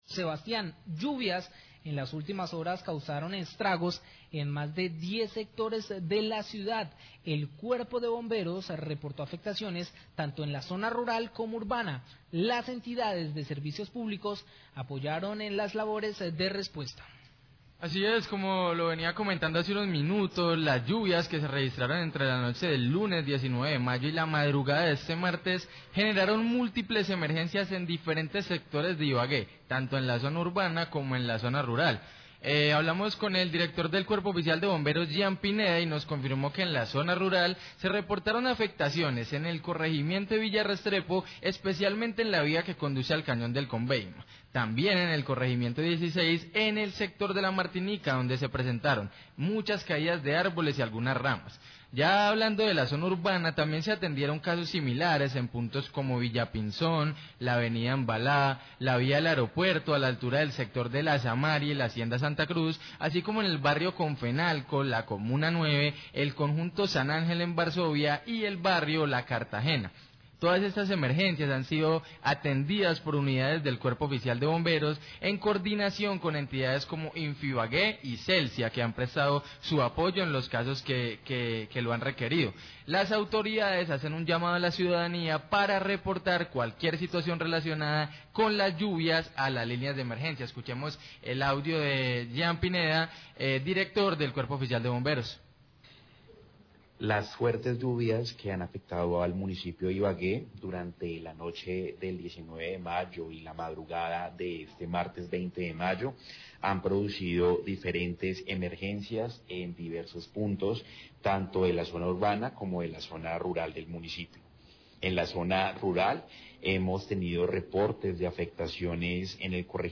Reporte de las emergencias causadas por las fuertes lluvias, Ecos del Combeima 1224pm
Radio